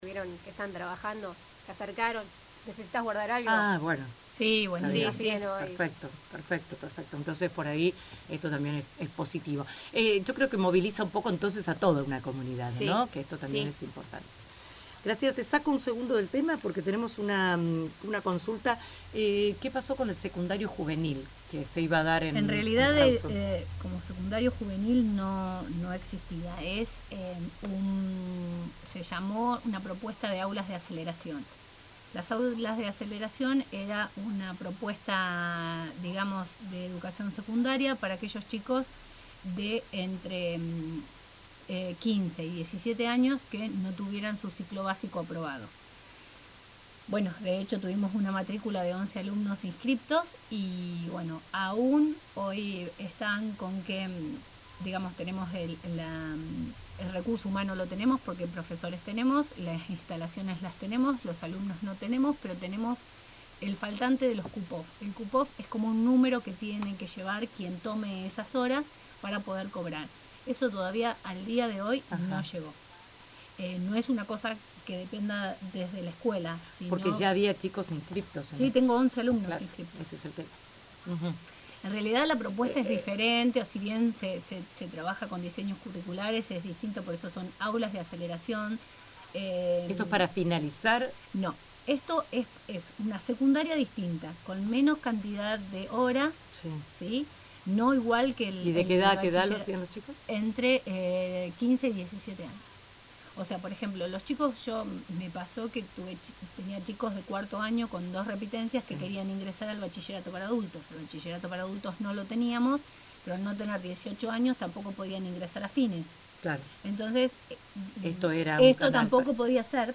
explicaban en el programa «Entre Nosotros»